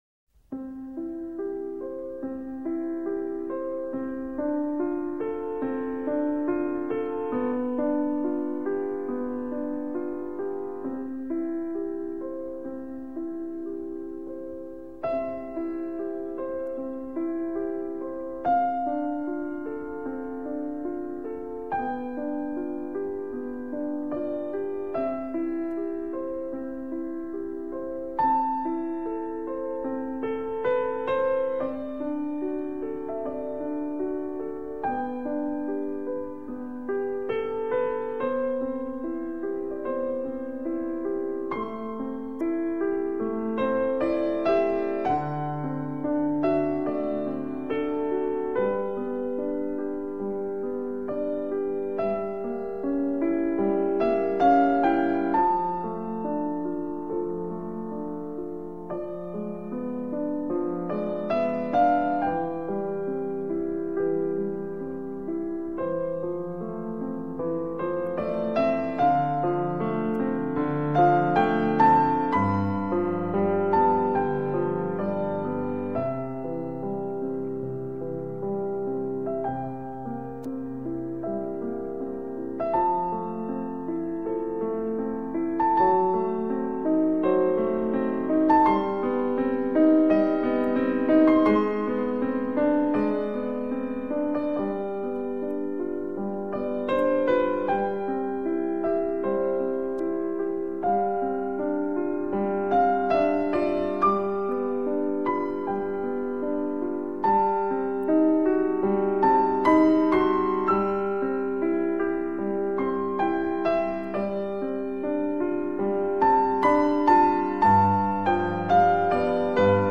0243-钢琴名曲圣母颂.mp3